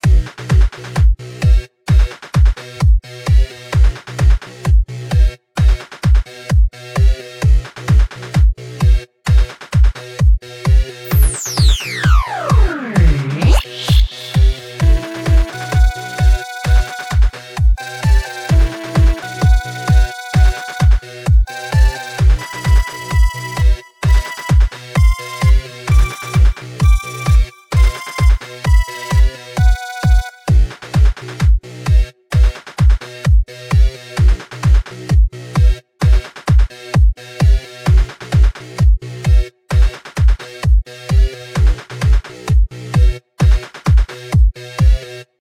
HI everyone, NEW MUSIC FOR YOUR PROJECTS!!!This MUSIC is LOOPABLE sooo don't worry!